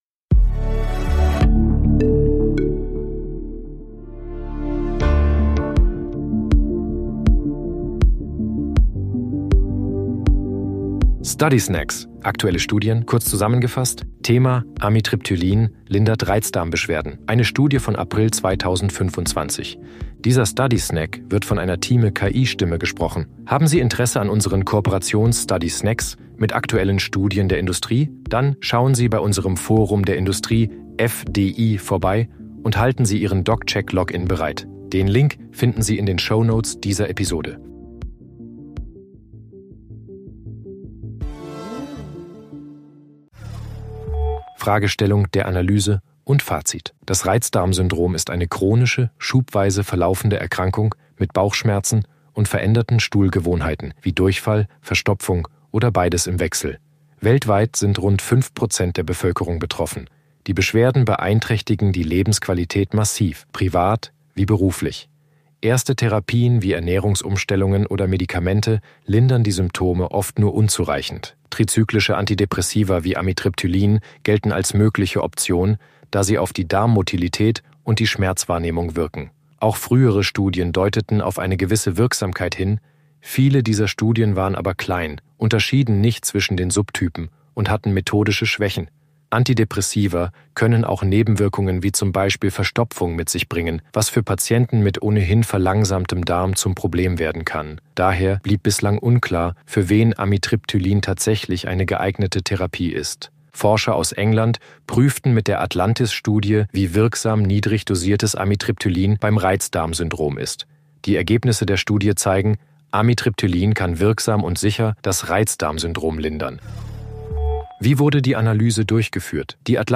sind mit Hilfe von künstlicher Intelligenz (KI) oder maschineller